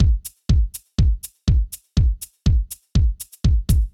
ORG Beat - Mix 5.wav